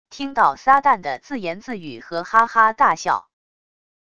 听到撒旦的自言自语和哈哈大笑wav音频